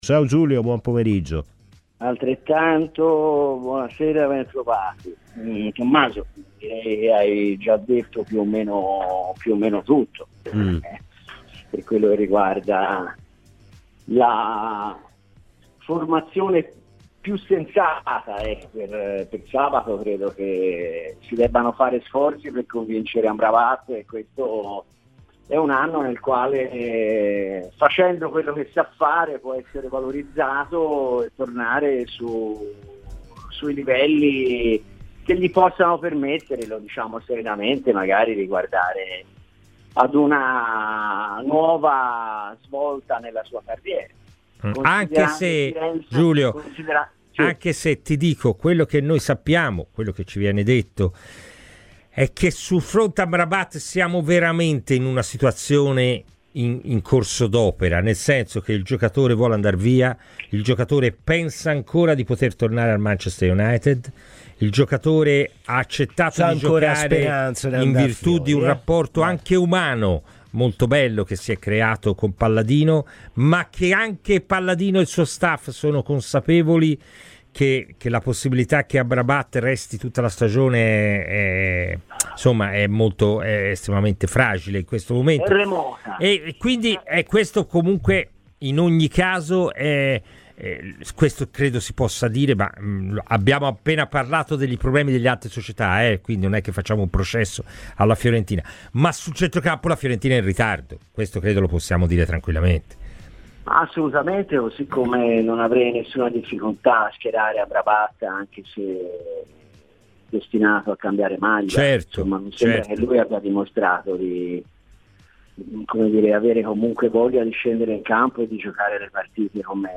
Ascolta il podcast per l'intervista integrale.